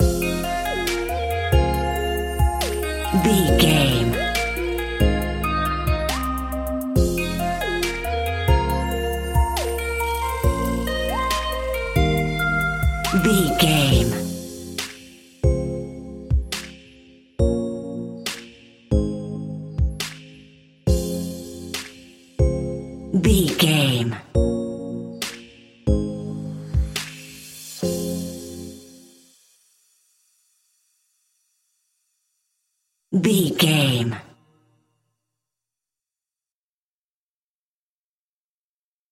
Aeolian/Minor
Slow
hip hop instrumentals
chilled
laid back
hip hop drums
hip hop synths
piano
hip hop pads